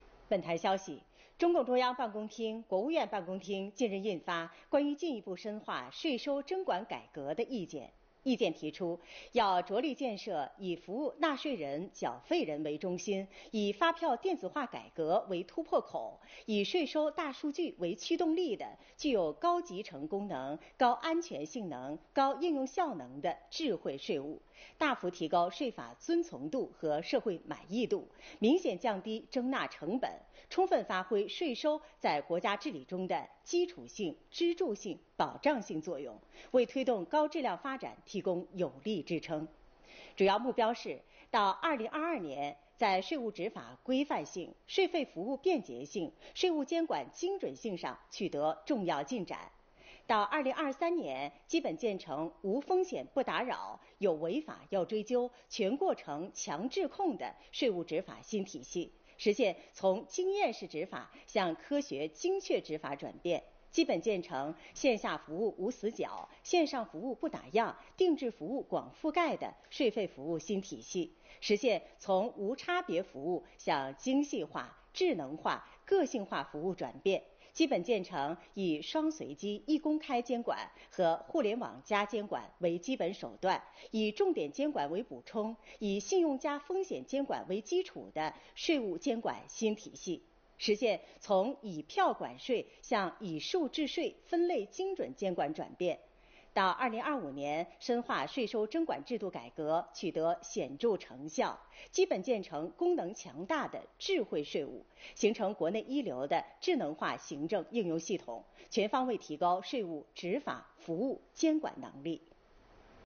视频来源：央视《新闻联播》